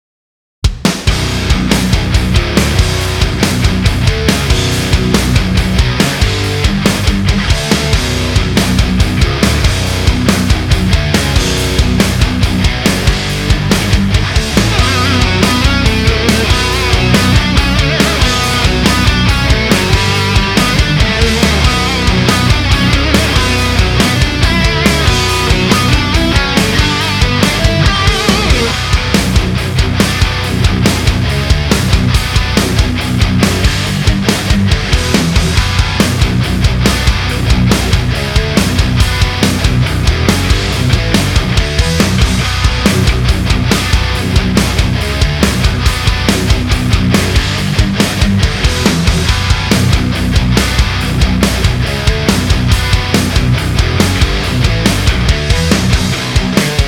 Тоже слегка повозился с демкой.
Насчет преампа - возможно не моё чтоли, перегруз я так понимаю серии "Марк", я в свое время с марк5 усилком посидел немного - мягко говоря нежными чувствами к нему не проникся, ну и тут какие то схожие ощущения.